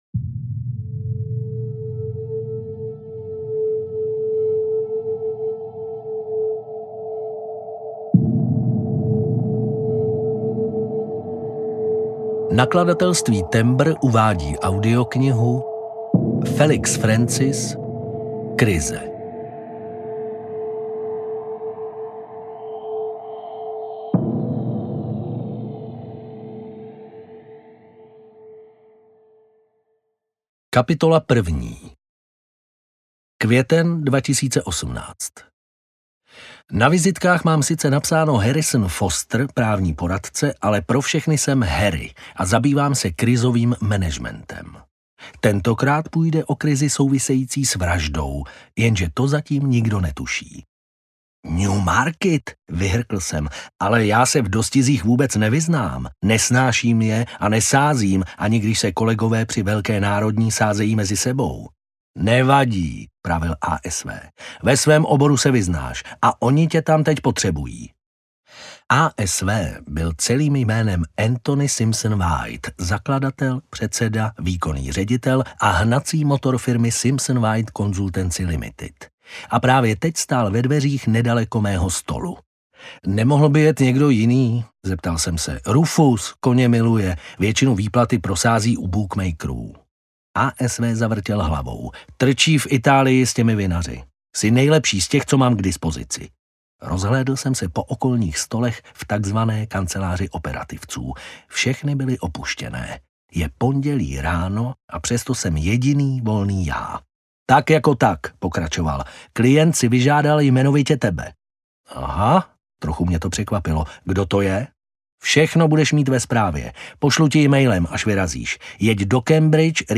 Krize audiokniha
Ukázka z knihy